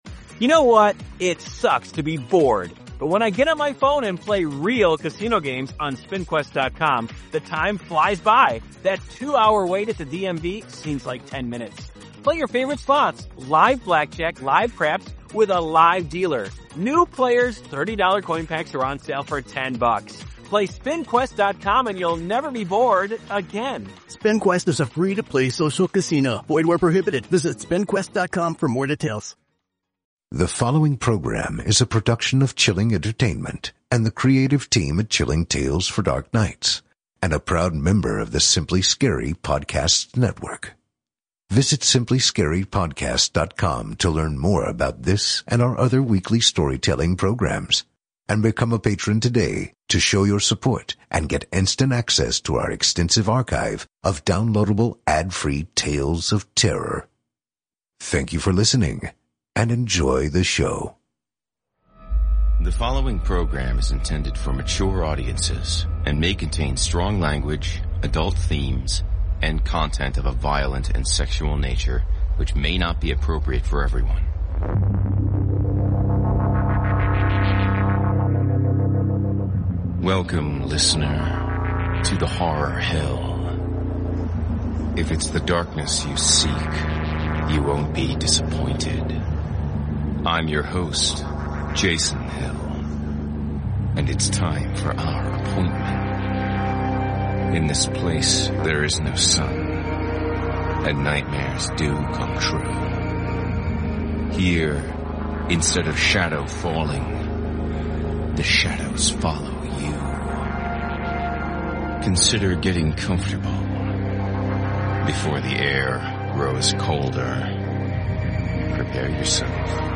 Stories performed
Sound design